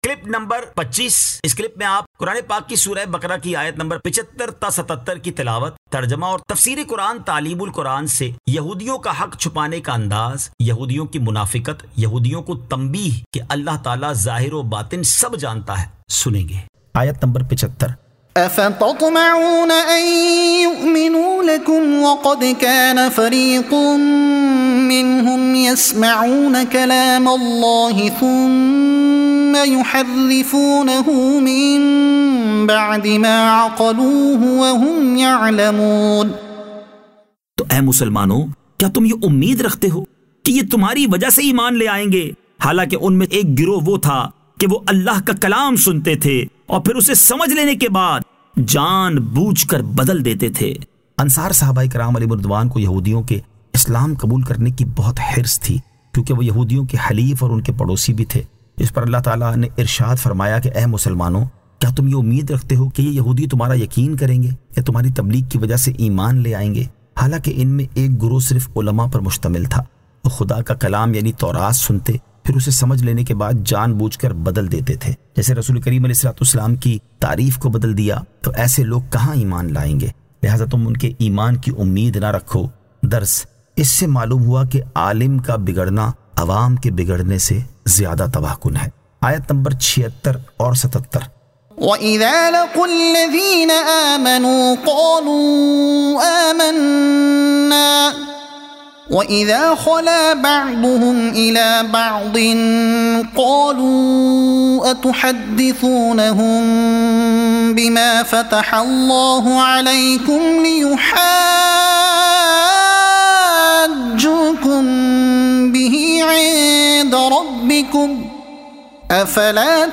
Surah Al-Baqara Ayat 75 To 77 Tilawat , Tarjuma , Tafseer e Taleem ul Quran